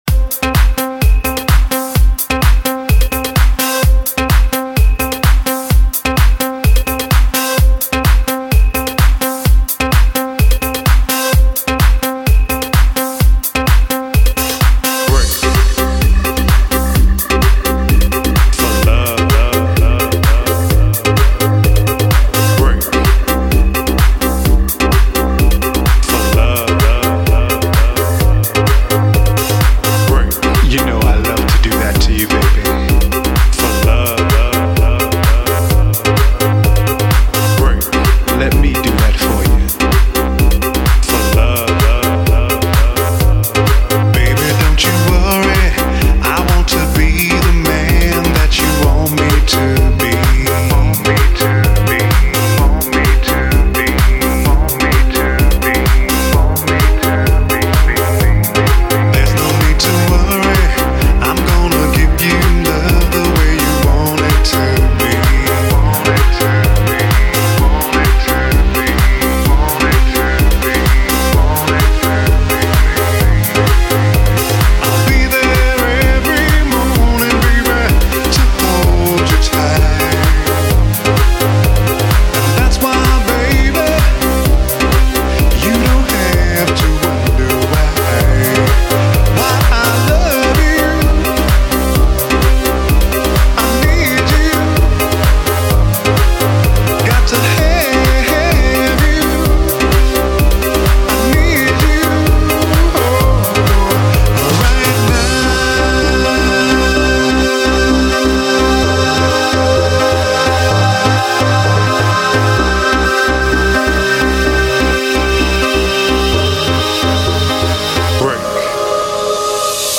Для любителей электронной музыки вот такой вот прикольный